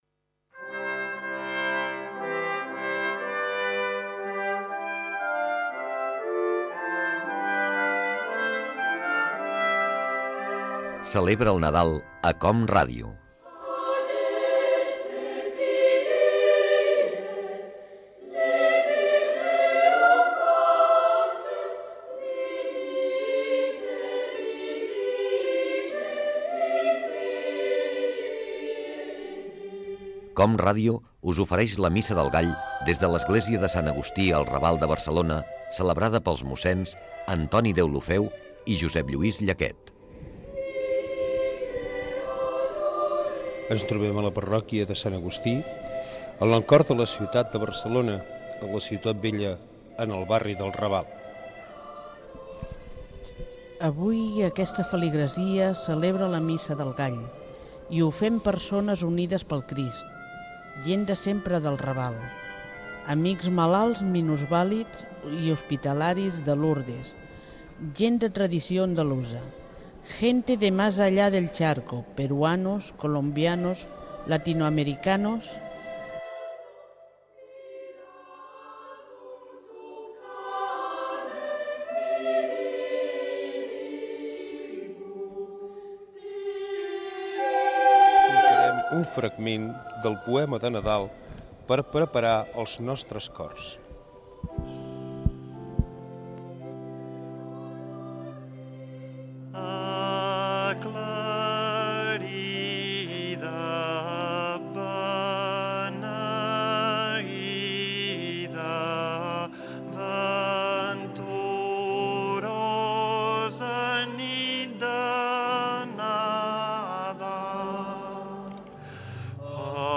Religió
FM